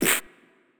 pbs - punched [ Perc ].wav